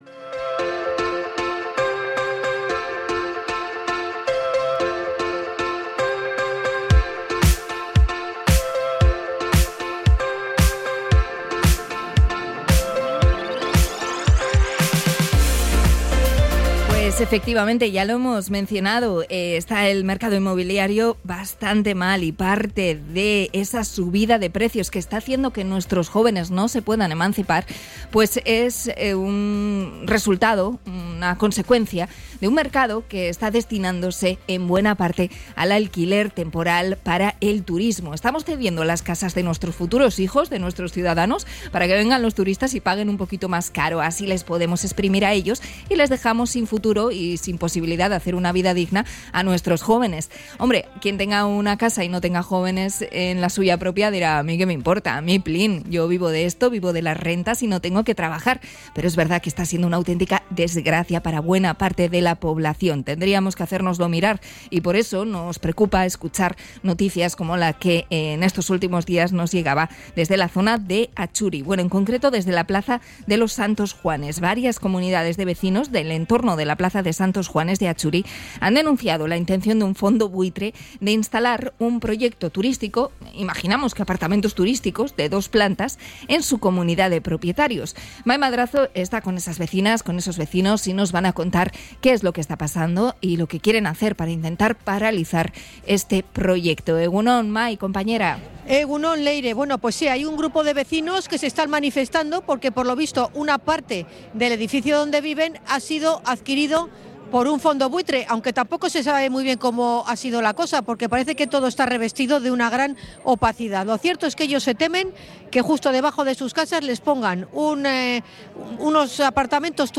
Hablamos con varias vecinas de las comunidades de propietarios afectadas